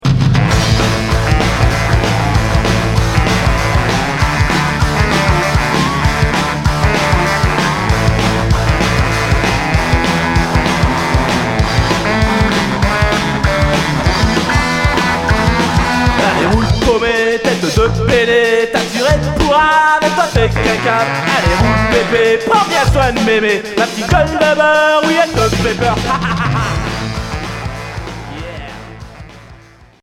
Rock punk